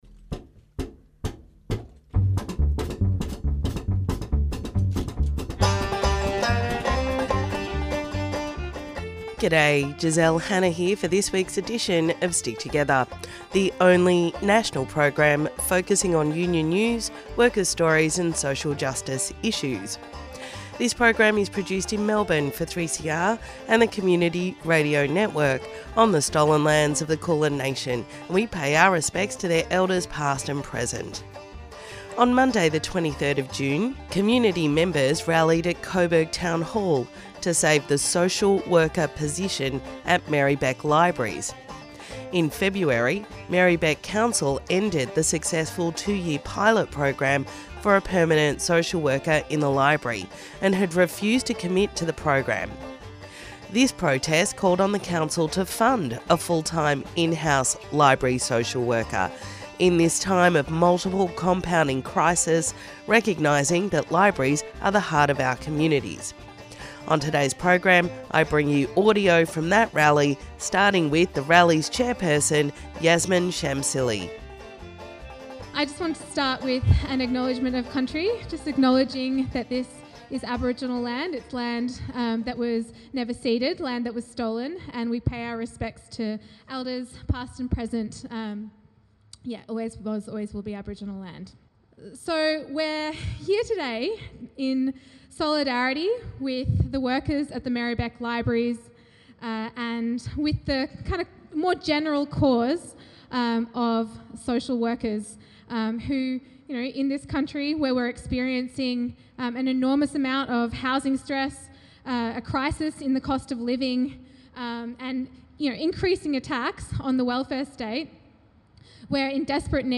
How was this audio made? On Monday 23rd June, community members rallied at Coburg Town Hall to save the social worker position at Merri-bek libraries.